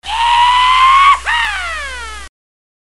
S – YEE HAW
S-YEE-HAW.mp3